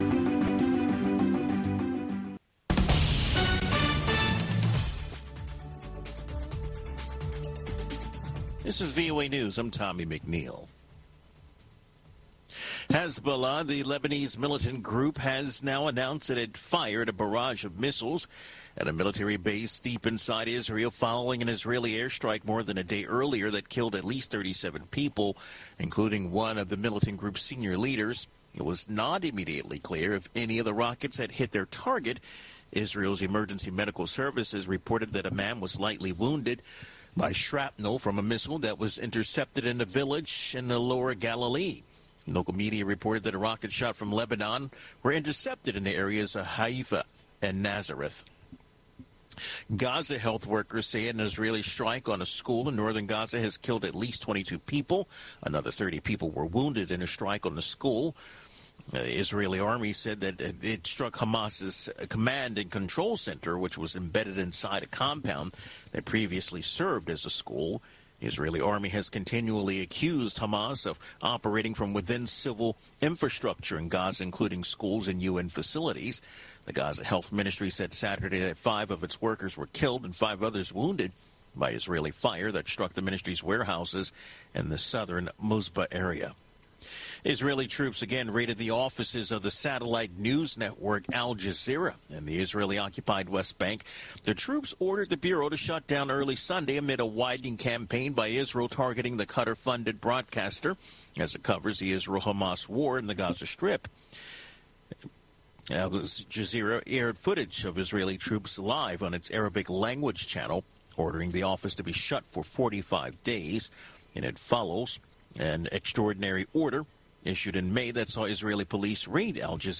Two Minute Newscast